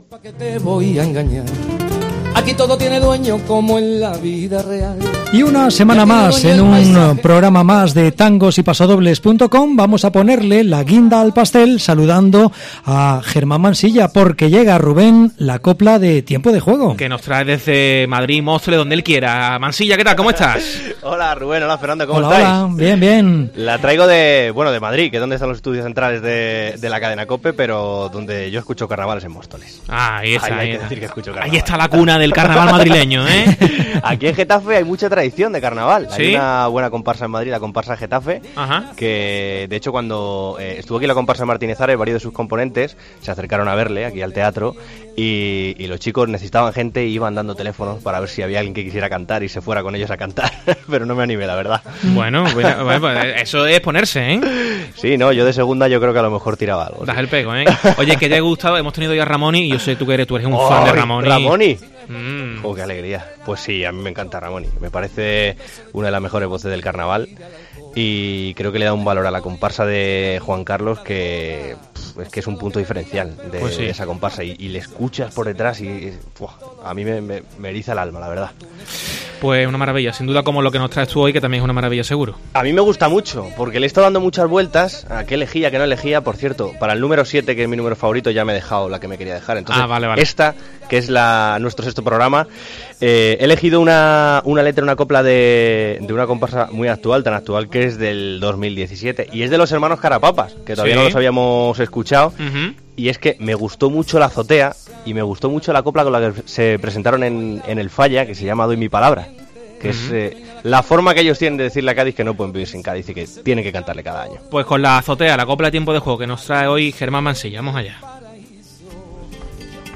una copla de la comparsa